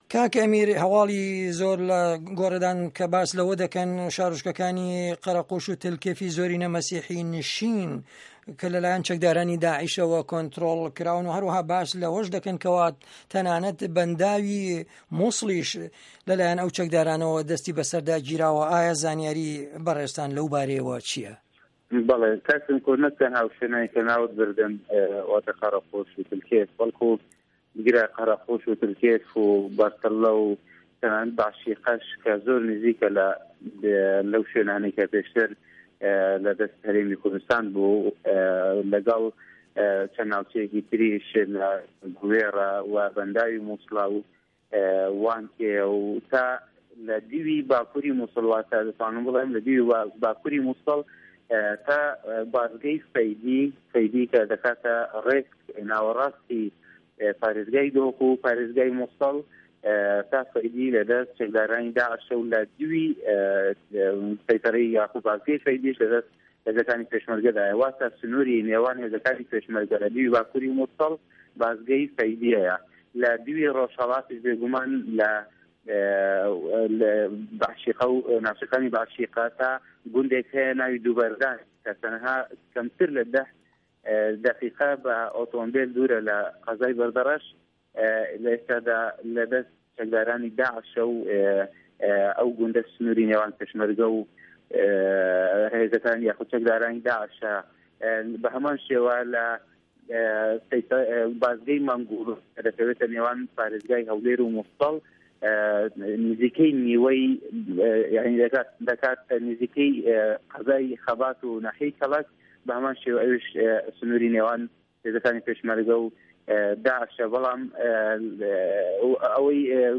ڕاسته‌وخۆ له‌ به‌رنامه‌کانی ڕادیۆ